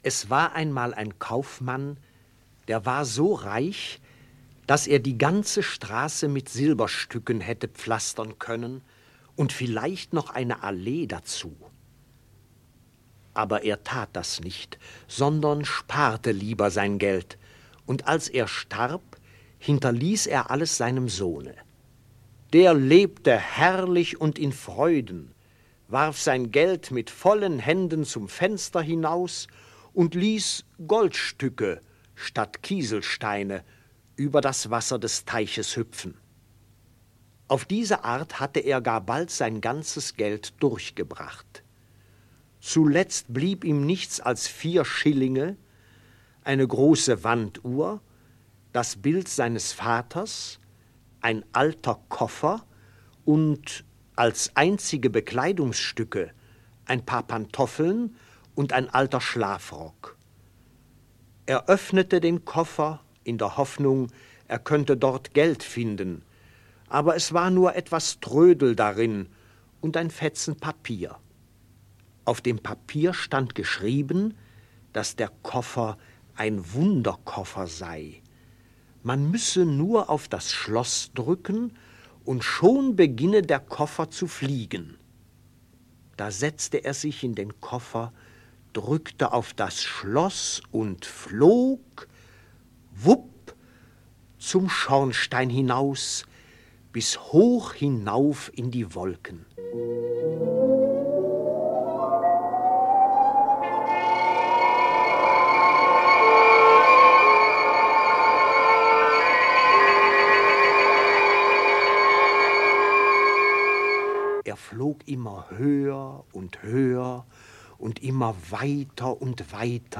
• AudioKniha ke stažení Der fliegende Koffer / Die Bremer Stadtmusikanten
V roce 1964 vydal Supraphon (Supraphon-Artia) pohádky Létající kufr a Brémští muzikanti v německé verzi - nyní tyto tituly vydáváme poprvé digitálně.